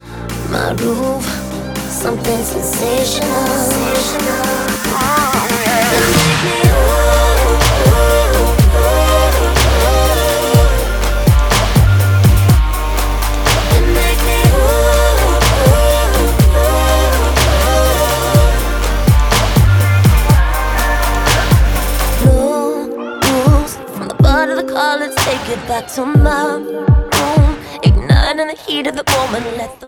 • Pop